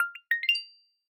sndDirectMsg.wav